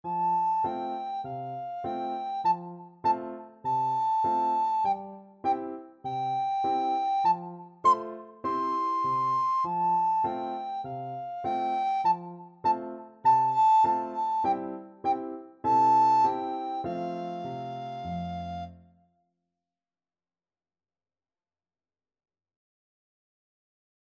Free Sheet music for Soprano (Descant) Recorder
Allegretto
C major (Sounding Pitch) (View more C major Music for Recorder )
4/4 (View more 4/4 Music)
F6-C7
Traditional (View more Traditional Recorder Music)
mary_had_a_little_lamb_REC.mp3